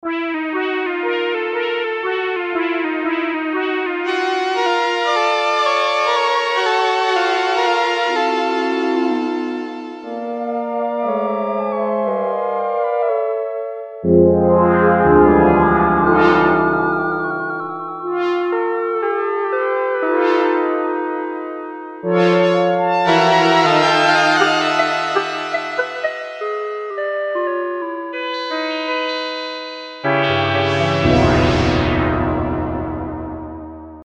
A signature bank from UltraRare, built around the signature feature of the Tiracon 6v’s envelope—the Break Point. This sound is difficult to achieve with any other instrument.